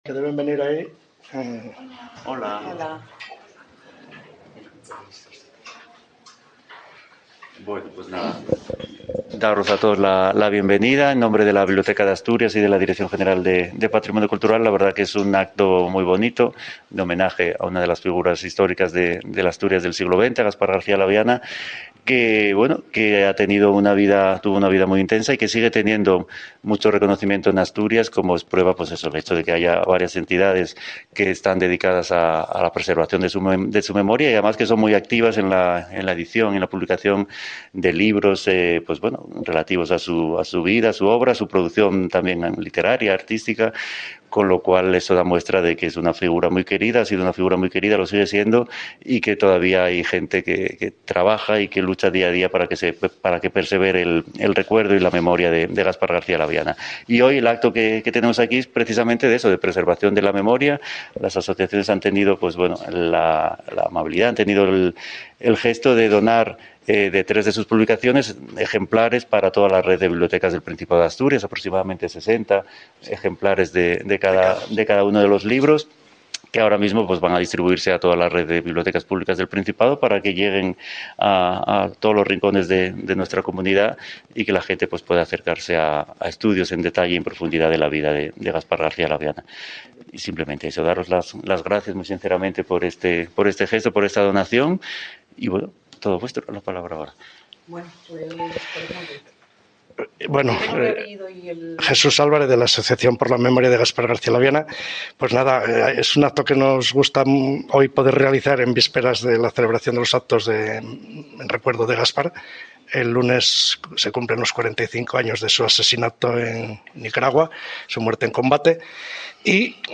ENTREVISTA EN LA CADENA SER